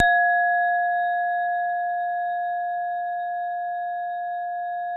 WHINE   F3-R.wav